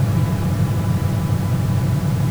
Bonus_Drone_4 thrust noise.wav